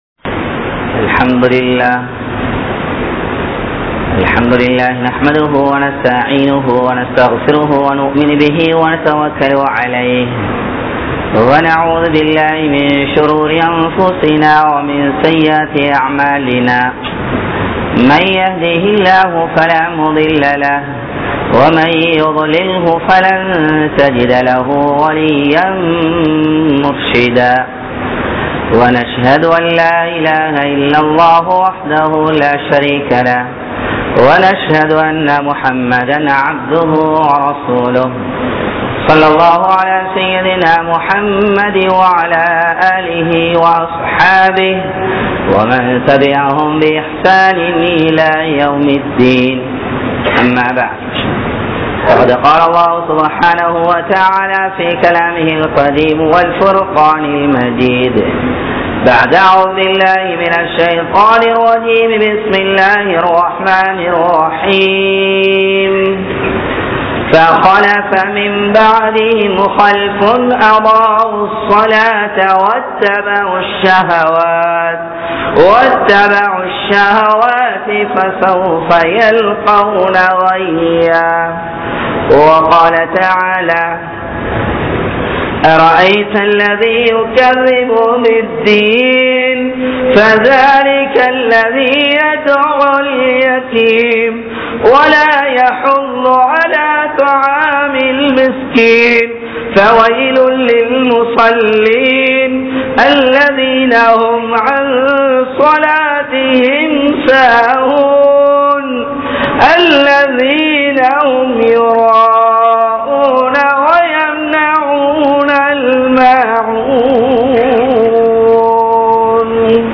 Tholuhaiel Alastsiyam Seium Manitharhal (தொழுகையில் அலட்சியம் செய்யும் மனிதர்கள்) | Audio Bayans | All Ceylon Muslim Youth Community | Addalaichenai